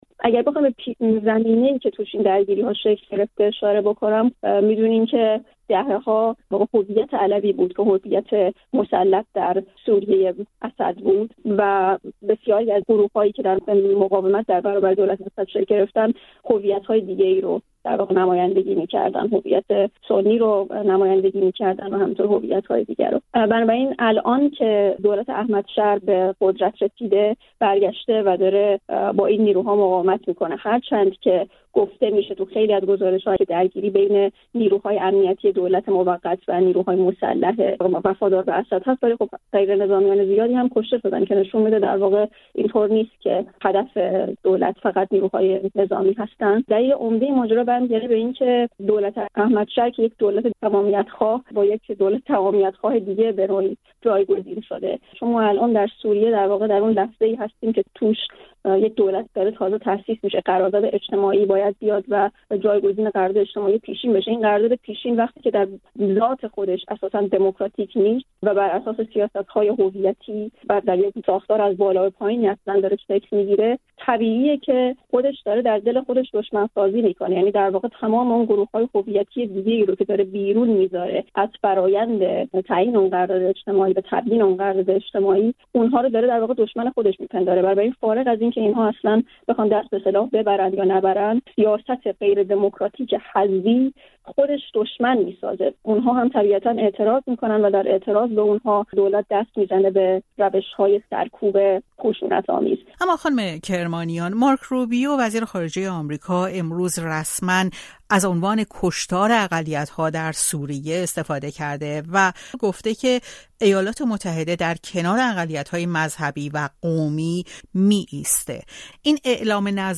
درگیری‌های جدید در سوریه در گفت‌وگو با یک پژوهشگر روابط بین‌الملل